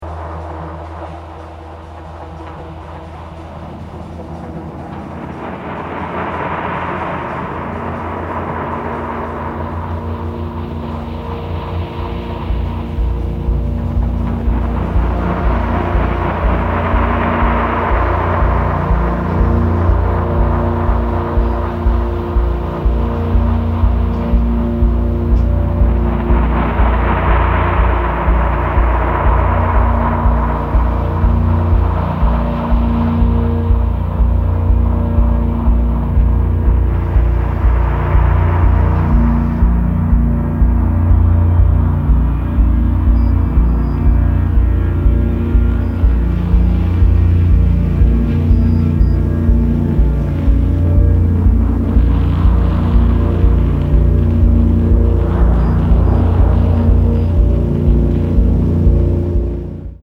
original motion picture score
entirely electronic and techno score